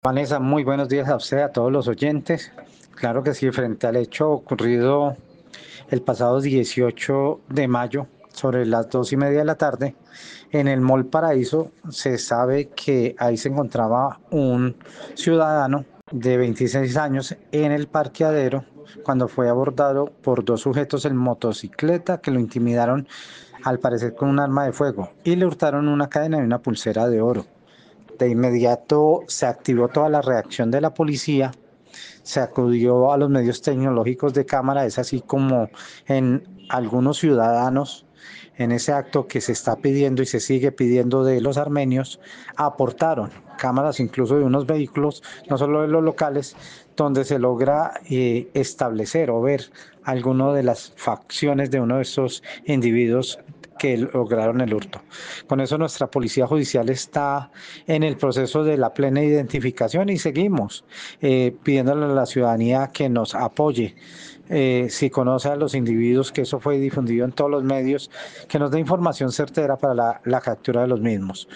Carlos Arturo Ramírez, secretario de Gobierno